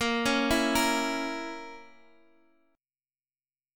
A#dim chord